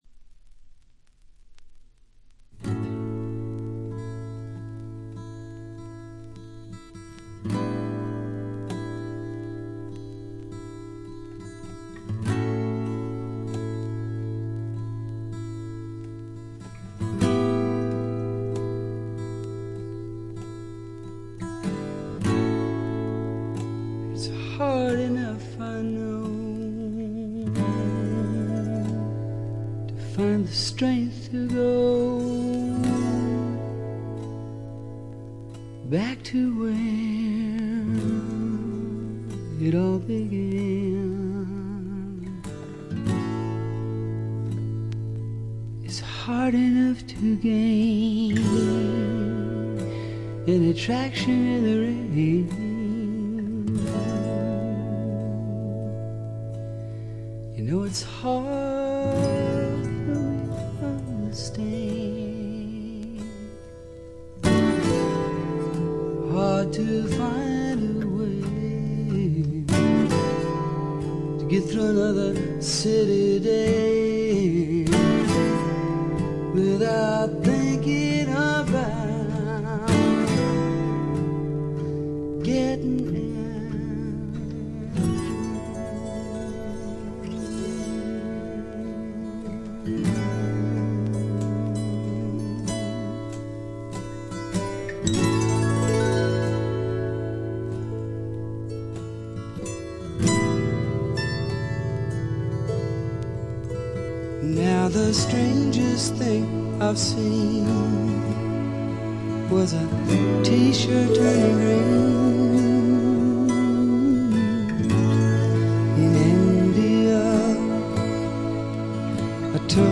全体に細かなチリプチが出ていますが、特に目立つノイズはなくA-寄りの良品です。
試聴曲は現品からの取り込み音源です。